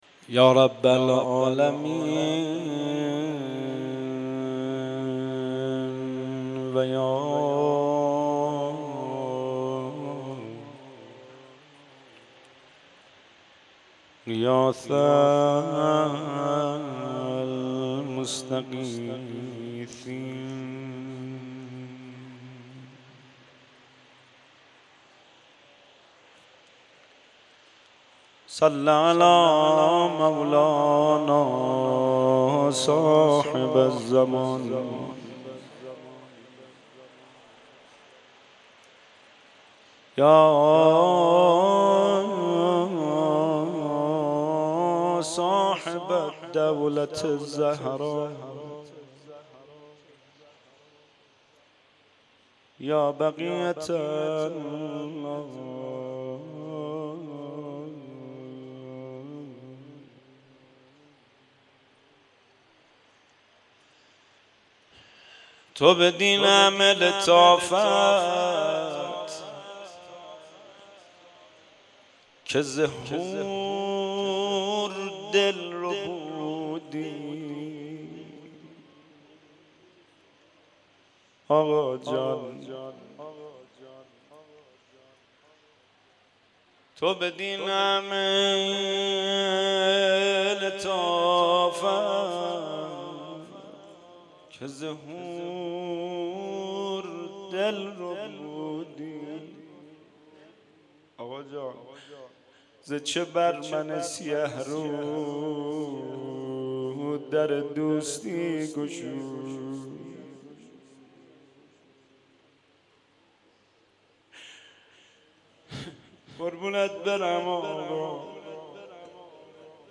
شهادت امام صادق علیه السلام-شب اول